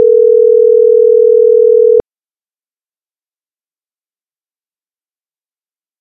phone-outgoing-calling.oga